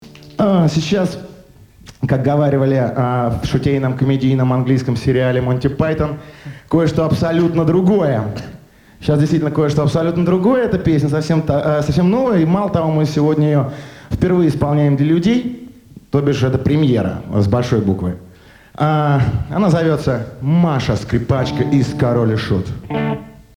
В интернете эти записи моно и качество 128, здесь стерео и 320.
Разговор в студии